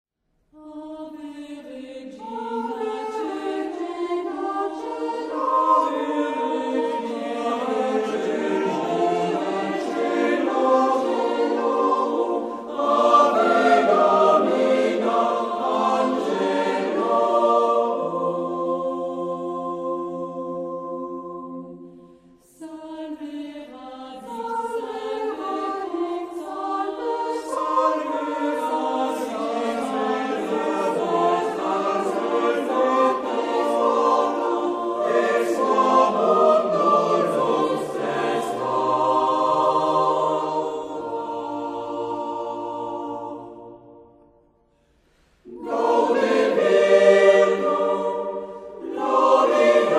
... A la manière grégorienne ...
Genre-Style-Form: Motet ; Sacred ; Antiphon
Mood of the piece: lively
Type of Choir: SATB  (4 mixed voices )
Tonality: B flat major